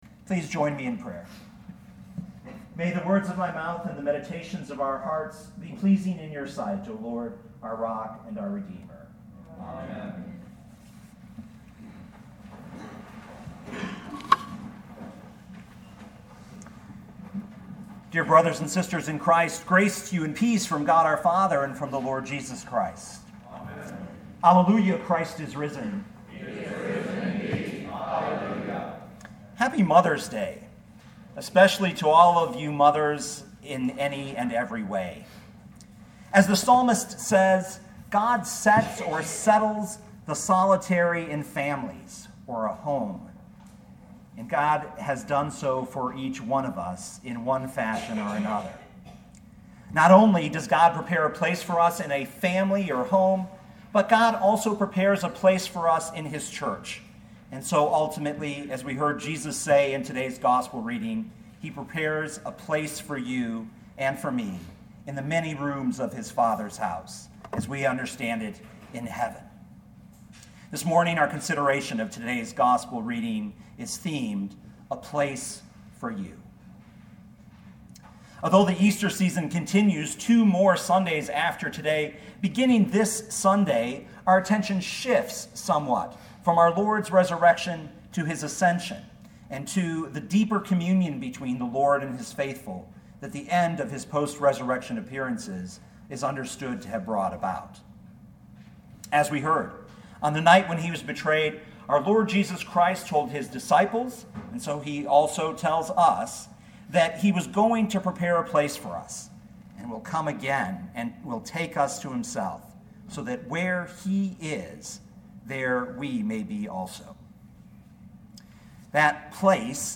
2017 John 14:1-14 Listen to the sermon with the player below, or, download the audio.